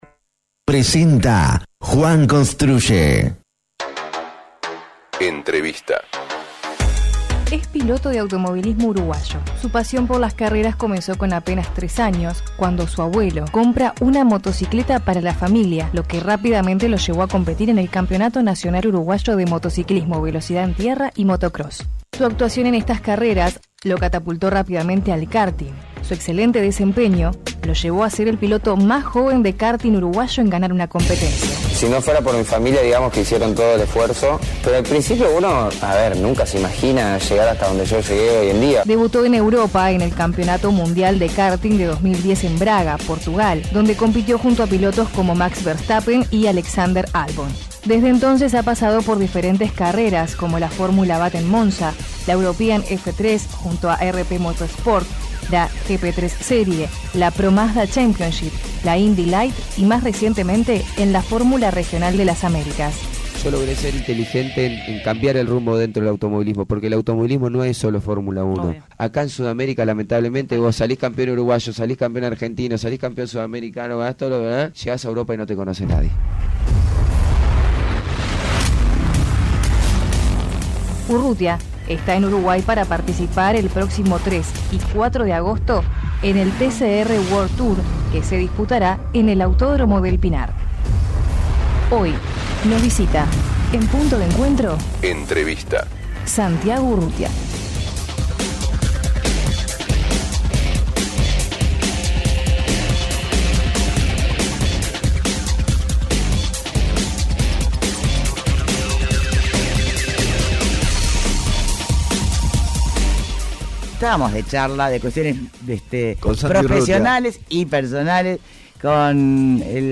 Entrevista a Santiago Urrutia: El piloto de automovilismo, Santiago Urrutia explicó su pasión por el automovilismo y la velocidad.